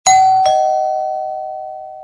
알림음(효과음) + 벨소리
알림음 8_띵동.mp3